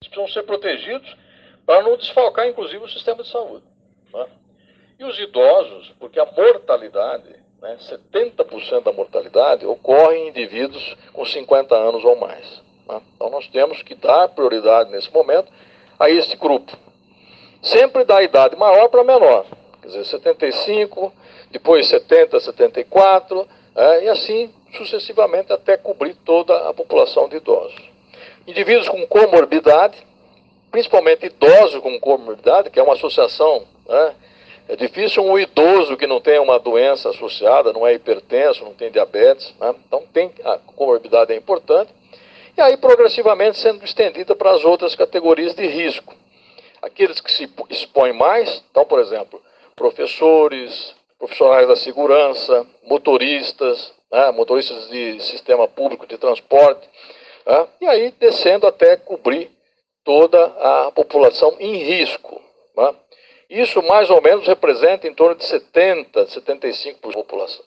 Dimas Covas em entrevista coletiva em Batatais (SP)
A declaração foi dada durante em entrevista coletiva no Instituto Clarentiano, na Unidade de Batatais, no interior paulista.